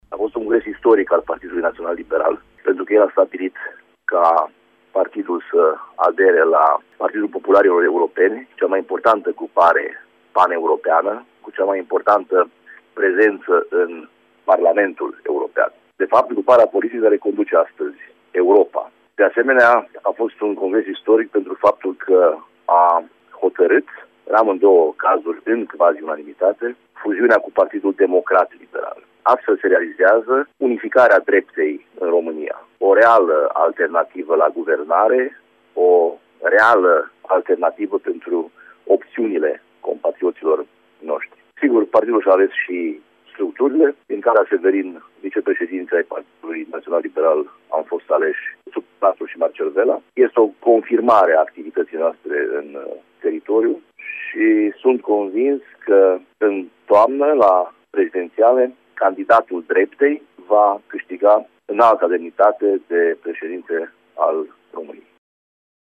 Sorin Frunzăverde, vicepreşedintele Partidului Naţional Liberal.